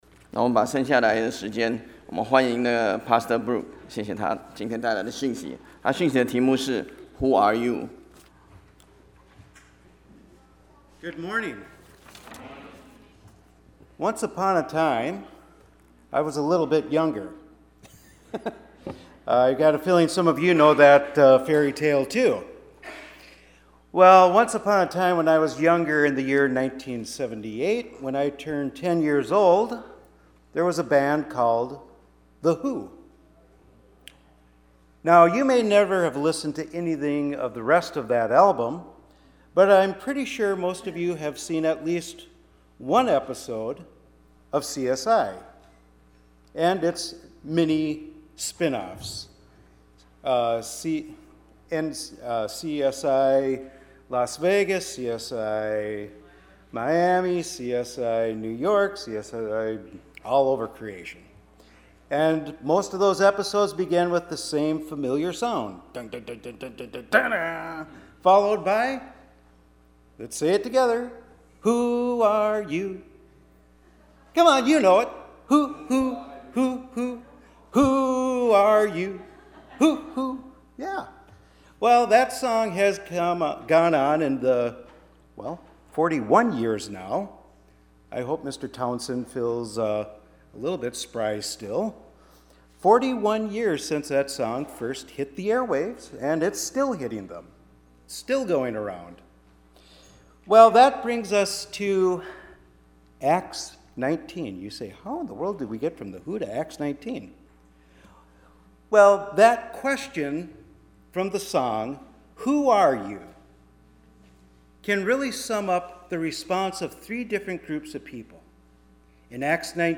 Bible Text: Acts 19:8-20 | Preacher